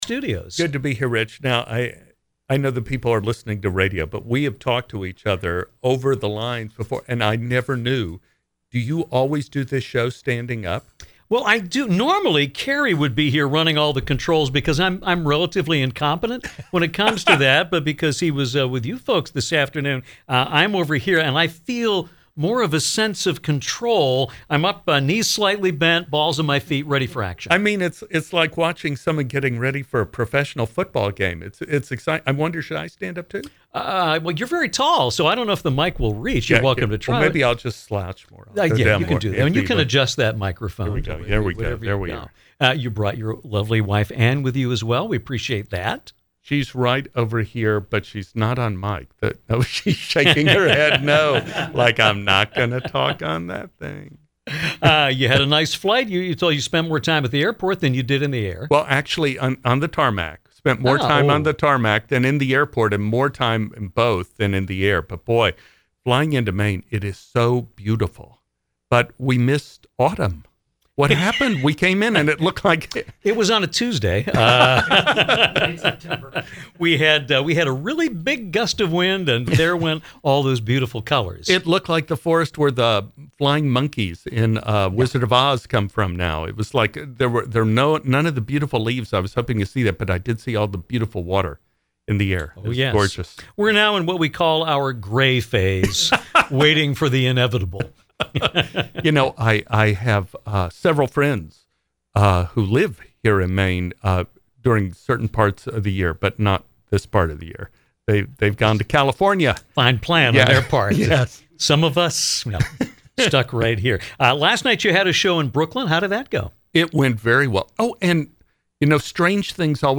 Actor Stephen Tobolowsky joined us in our studio to discuss tonight’s appearance in Brewer.